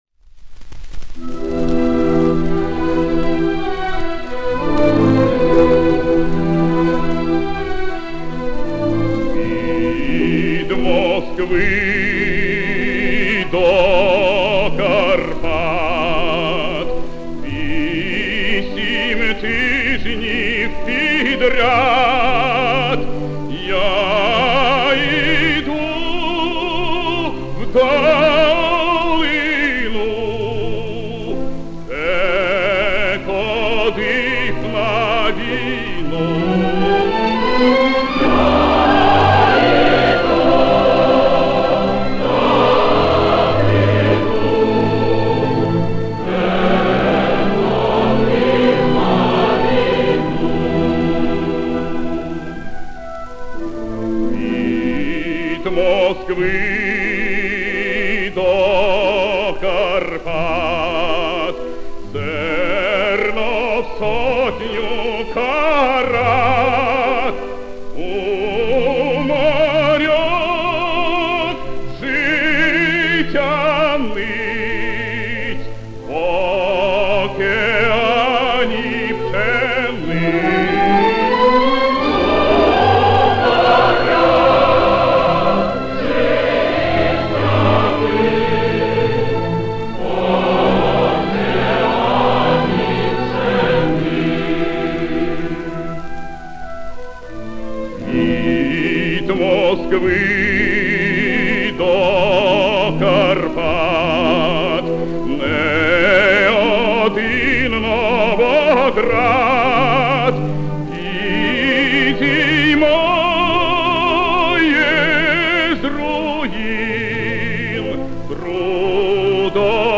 Повышение качества.